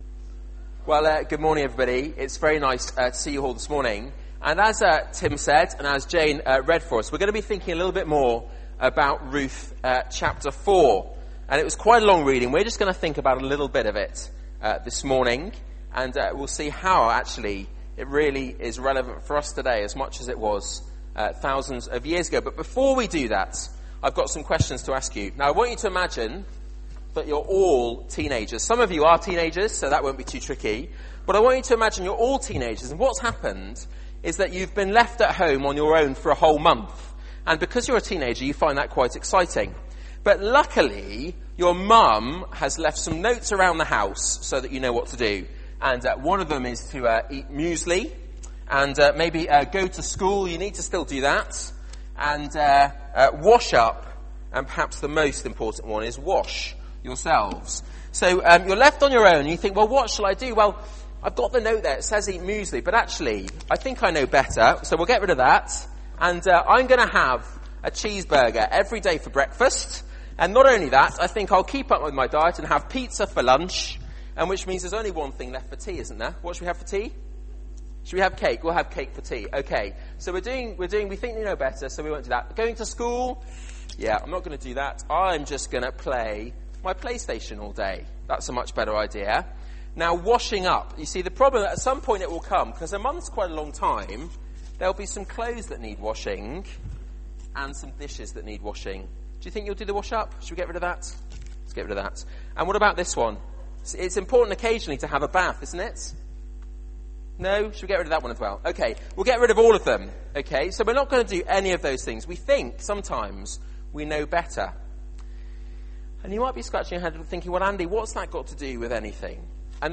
Media for 9:15am Service on Sun 06th Mar 2011 09:15 Speaker
Passage: Ruth 4 Series: From Famine to Fortune Theme: Sermon Search the media library There are recordings here going back several years.